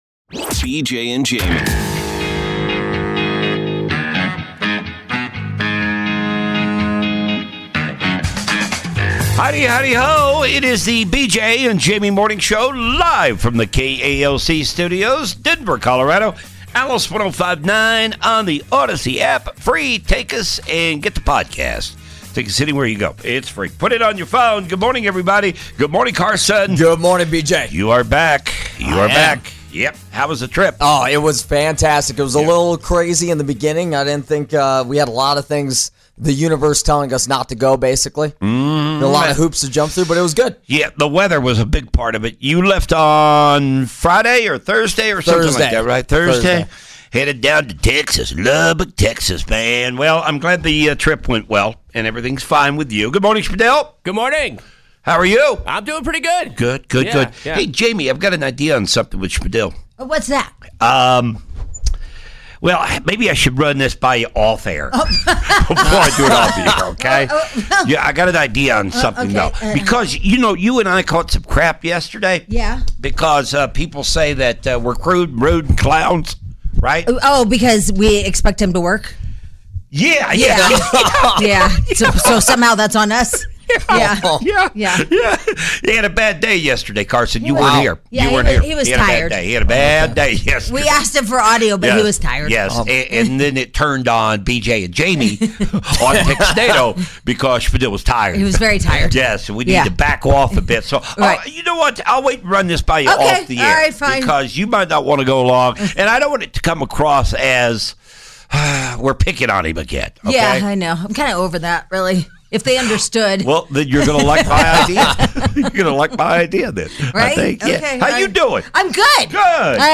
Irreverent, funny, and real-life radio